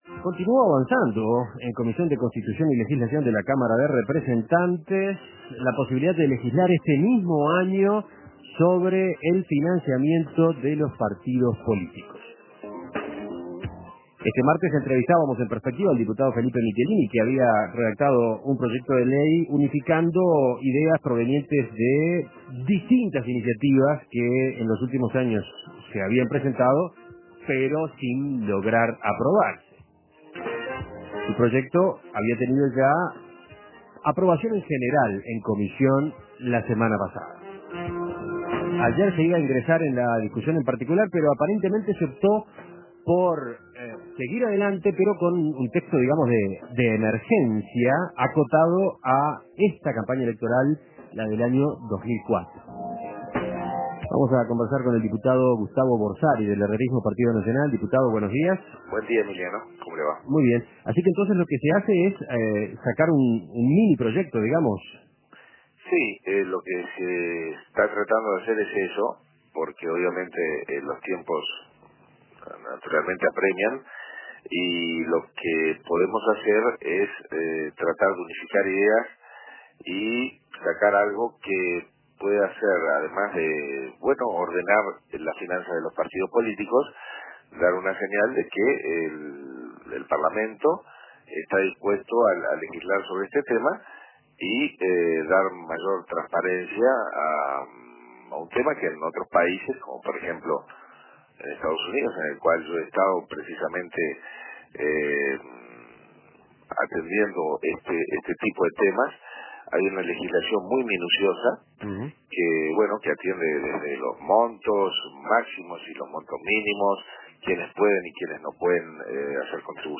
Entrevista con Gustavo Borsari.